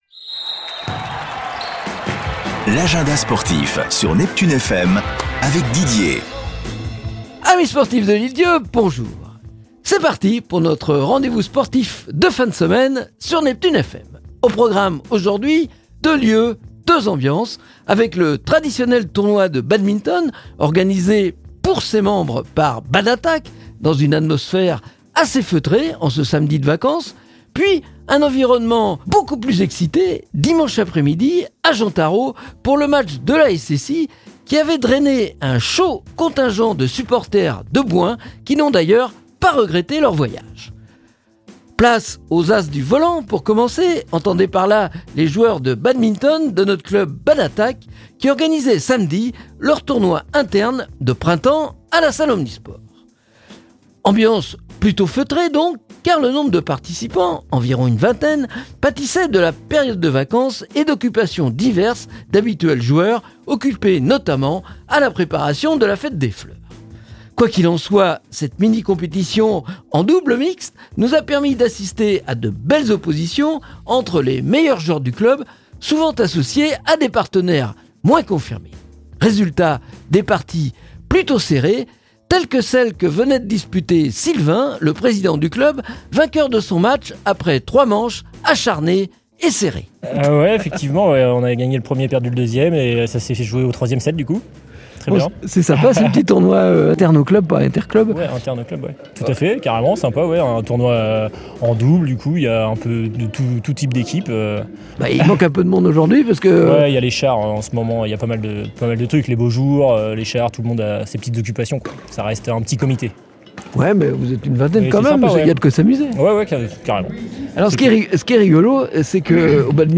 Avec, pour commencer, le traditionnel tournoi de badminton organisé samedi pour ses membres par l'association Bad'Attack, dans une atmosphère assez feutrée. Puis, un environnement beacoup plus exité, dimanche après-midi au stade Jean Taraud, pour le match de la SSI contre Bouin.